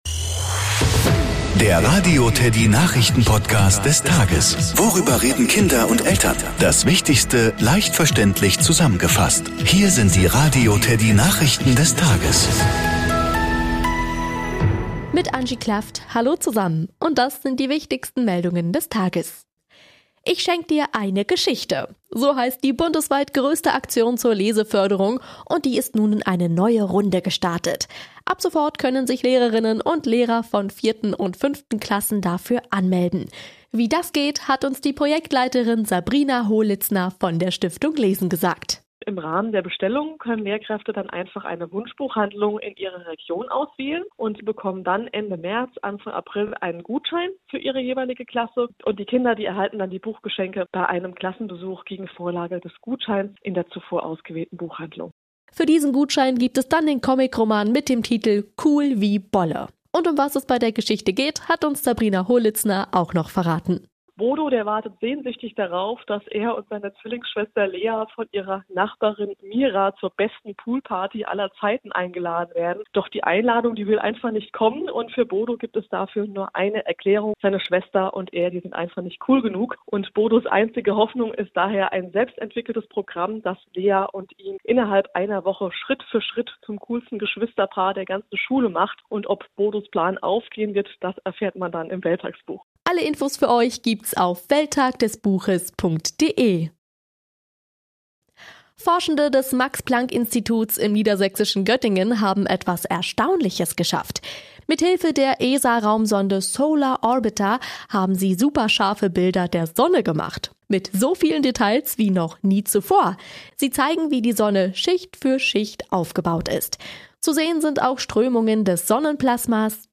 Nachrichten , Kinder & Familie